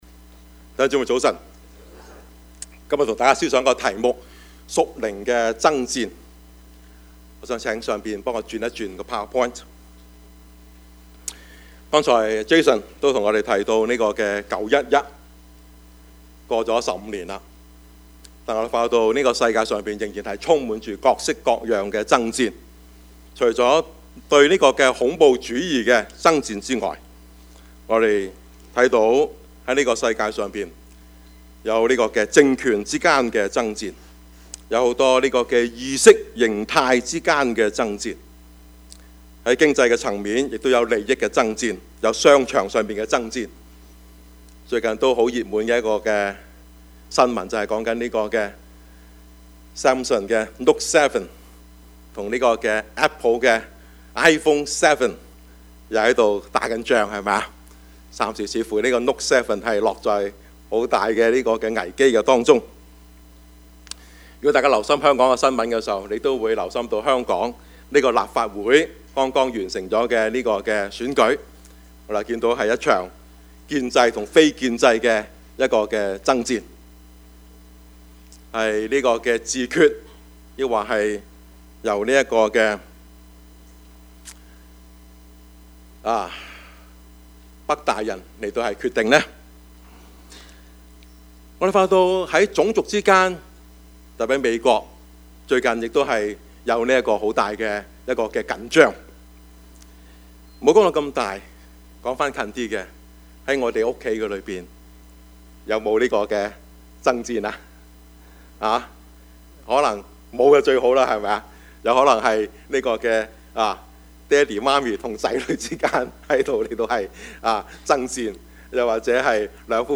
Service Type: 主日崇拜
Topics: 主日證道 « 信仰與工作 禁不了的喜樂 »